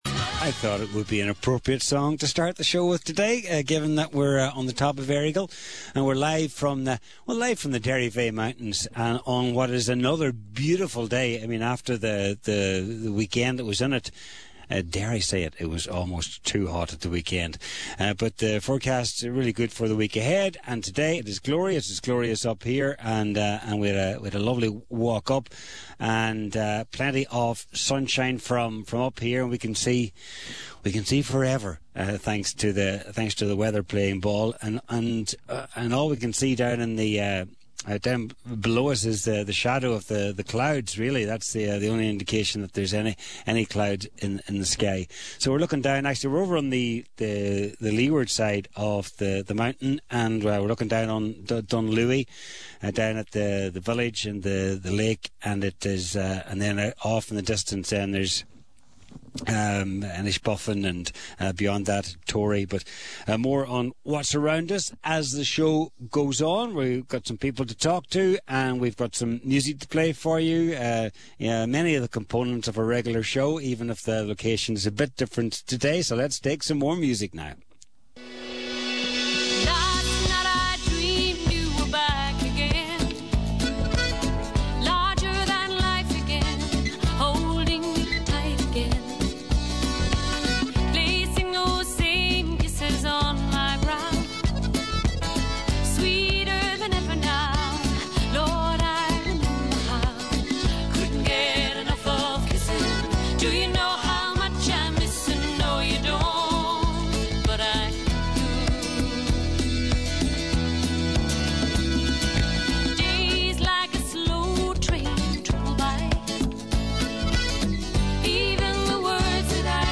We were joined by some very special guests on the day chatting to some locals and some people who are heavily involved within the community and the Mountain!